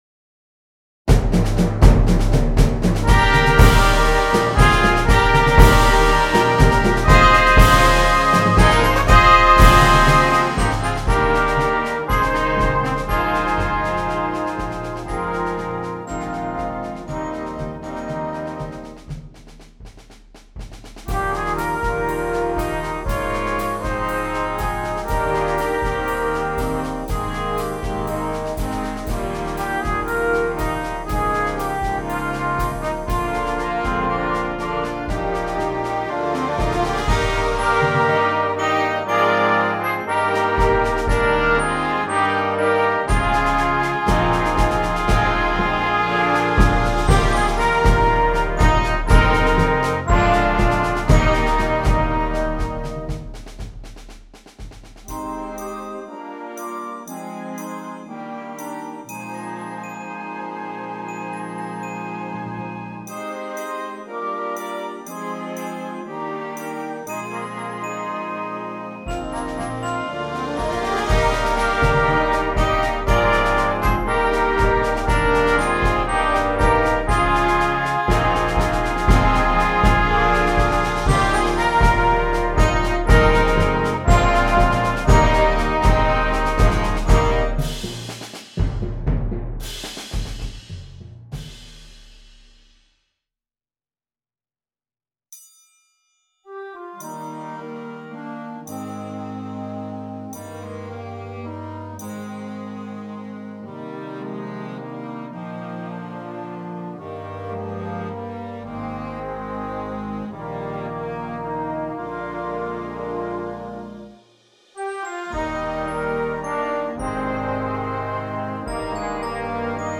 Concert Band
With bold harmonies, driving rhythms and contrasting styles